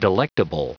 Prononciation du mot delectable en anglais (fichier audio)
Prononciation du mot : delectable